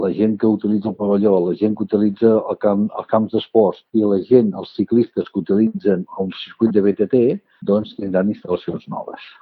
L’objectiu de l’Ajuntament és aconseguir una rebaixa del preu final per poder invertir en altres equipaments de la zona esportiva. Colomí a Ràdio Capital.